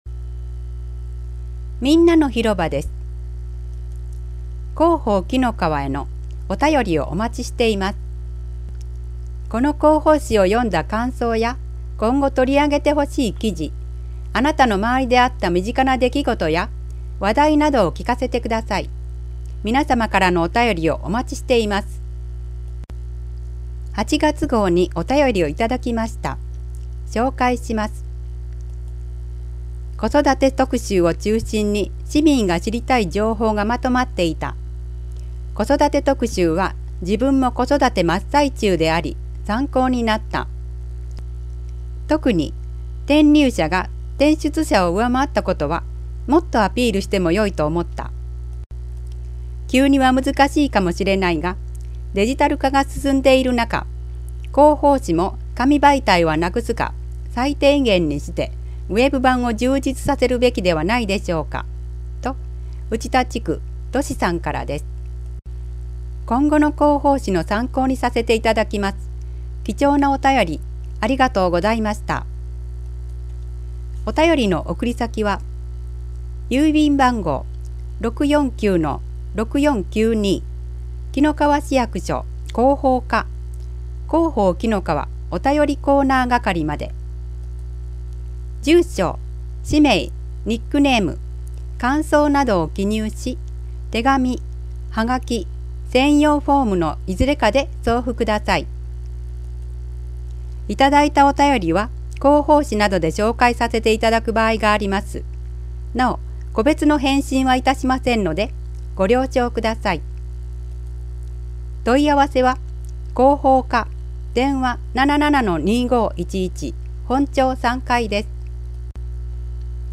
声の広報紀の川（令和5年9月号）｜紀の川市